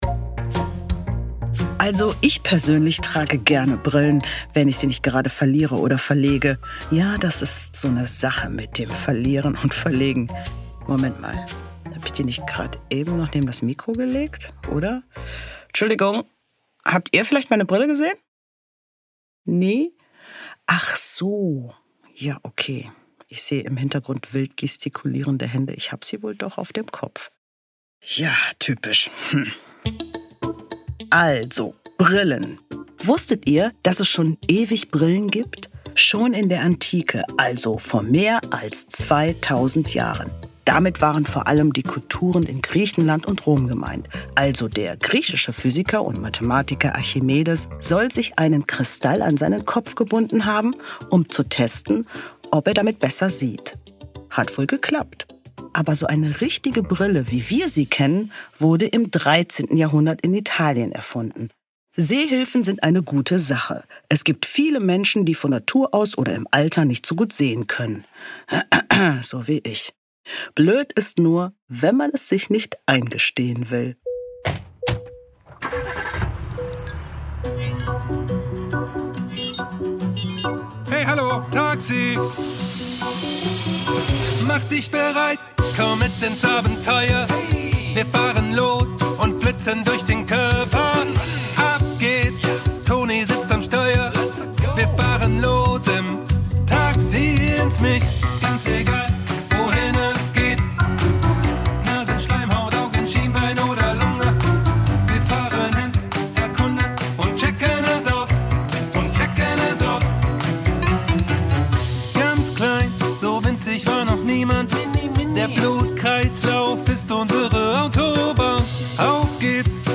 Hicks, hicks, hurra! – Taxi ins Mich | Der Hörspiel-Podcast für Kinder – Podcast